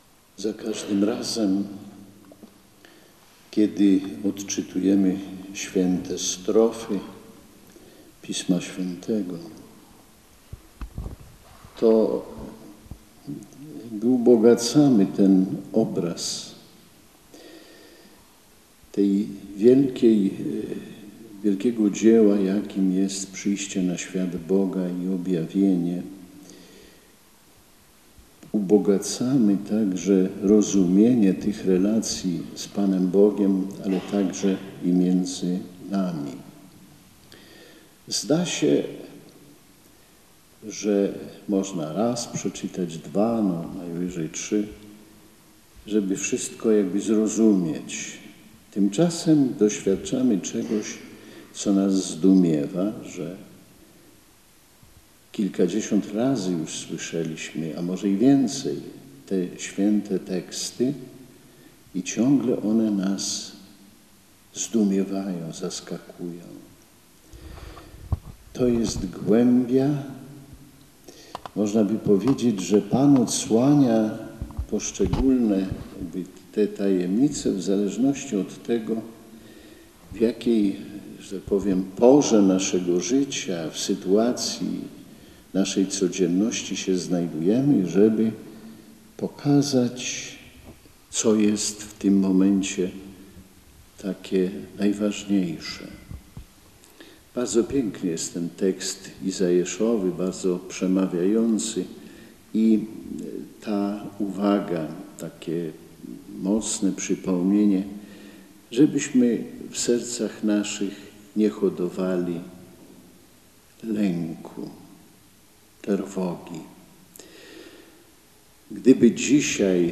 Jesteście potrzebne – mówił biskup Romuald Kamiński podczas Mszy świętej odprawionej w intencji sióstr zakonnych z diecezji warszawsko-praskiej w kaplicy kurialnej.
homiliabprom.mp3